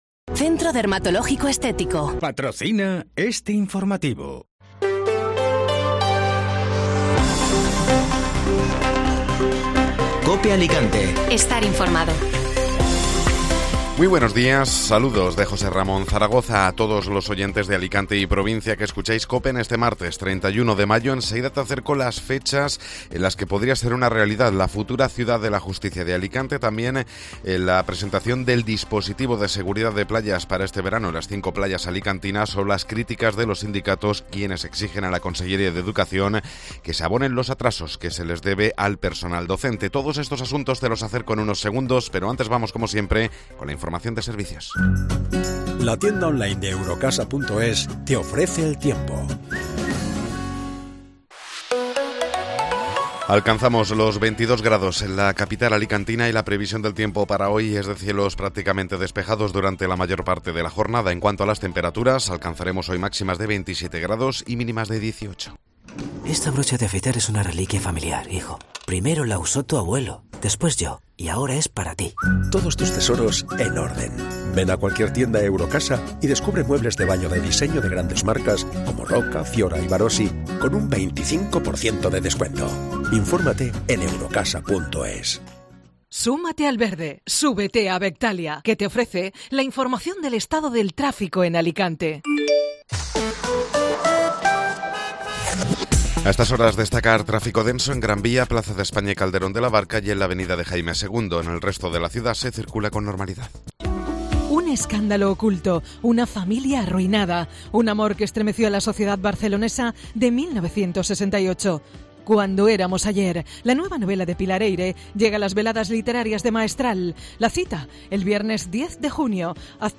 Informativo Matinal (Martes 31 de Mayo)